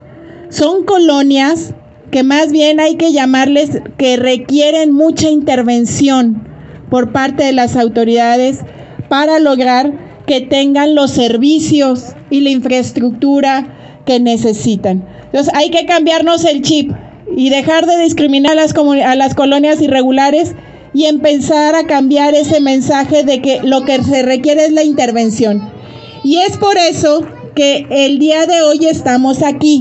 AudioBoletines
Lorena Alfaro García – Presidenta Municipal